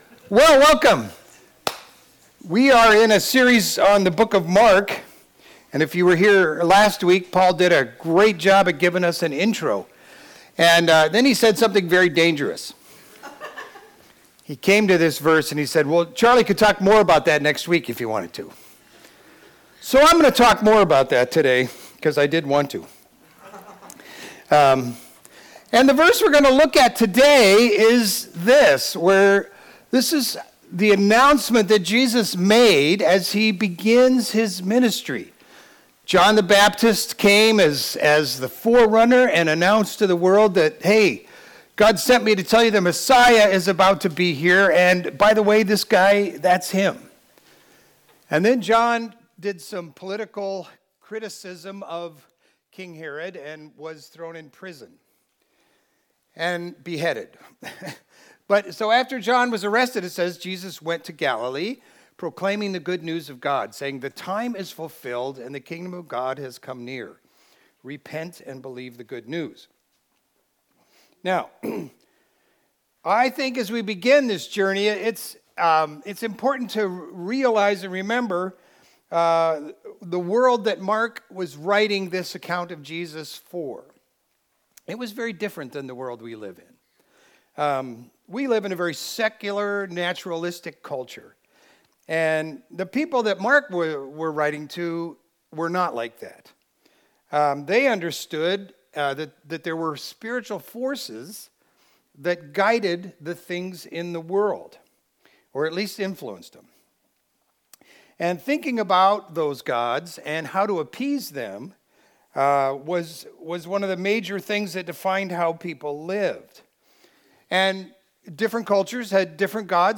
Video Audio Download Audio Home Resources Sermons The Time is Fulfilled Apr 19 The Time is Fulfilled Jesus starts his ministry with a world changing announcement.